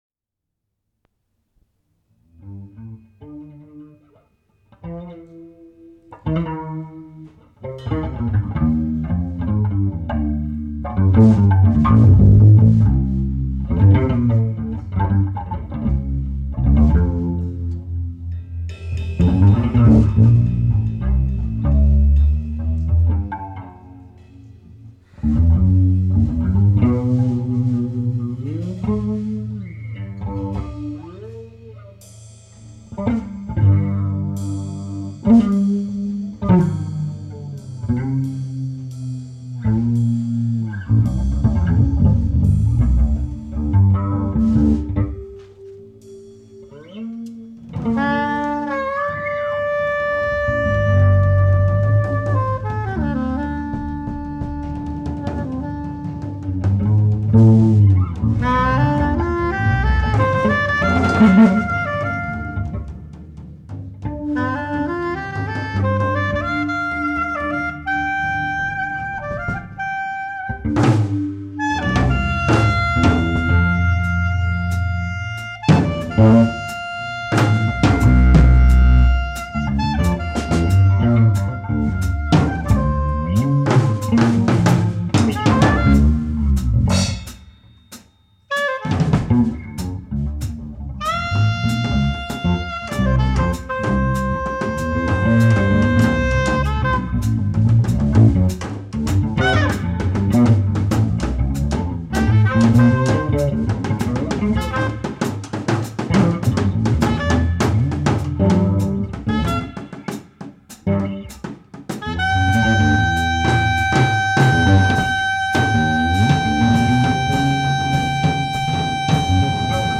sax
bassa
trommur
gítar